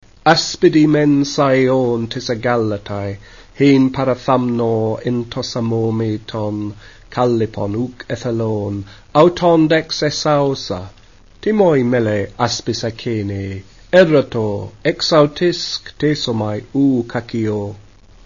spoken version